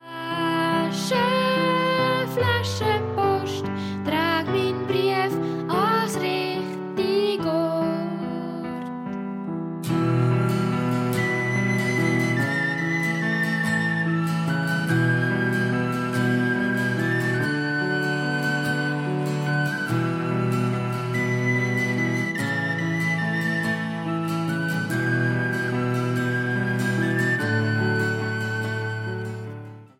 Mundartlieder für Chinderchile